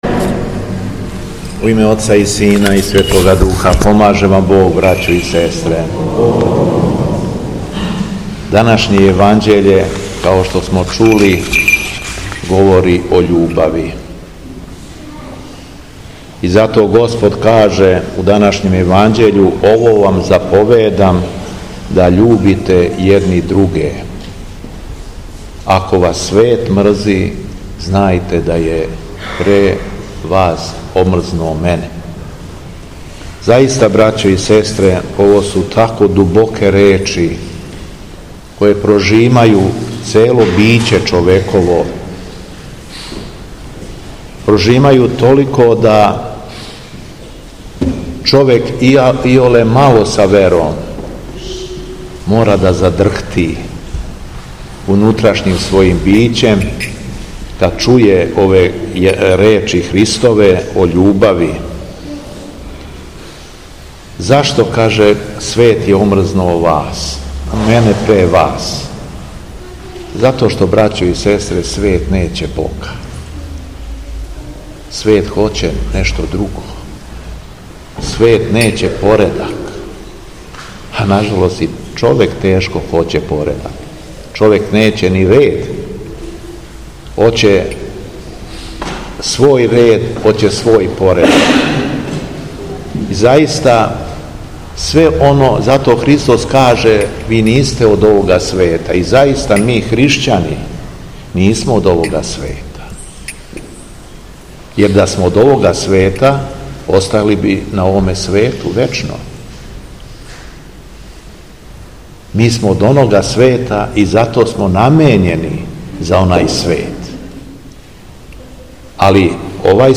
Беседа Његовог Високопреосвештенства Митрополита шумадијског г. Јована
Митрополит Јован поучио је верни народ пригодном беседом након прочитаних одељака из Светог писма Новог завета: